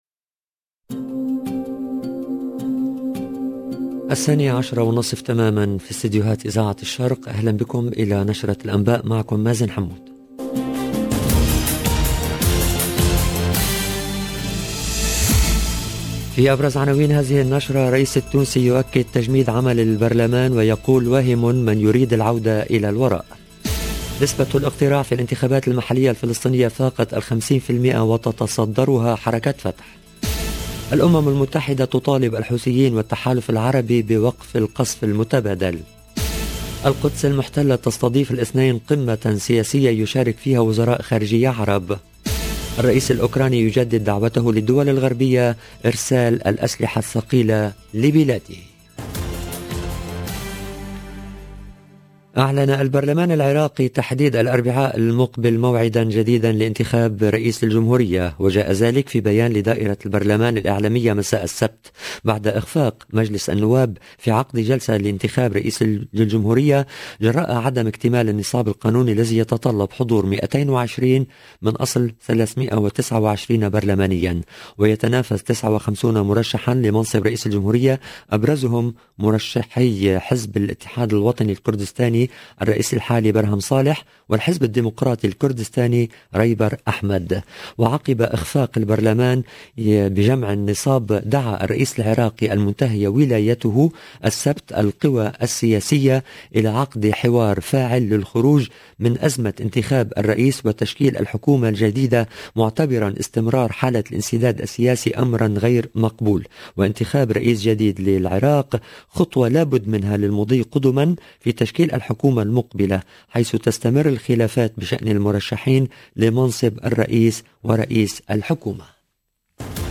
EDITION DU JOURNAL DE 12H30 EN LANGUE ARABE DU 27/3/2022